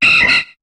Cri d'Hélédelle dans Pokémon HOME.